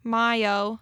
(MĪ-ō)